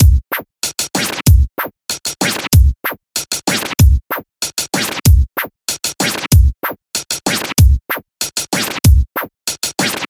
Tag: 95 bpm Electronic Loops Drum Loops 1.70 MB wav Key : Unknown